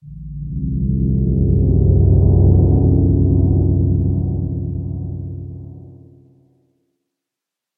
Minecraft Version Minecraft Version latest Latest Release | Latest Snapshot latest / assets / minecraft / sounds / ambient / cave / cave3.ogg Compare With Compare With Latest Release | Latest Snapshot
cave3.ogg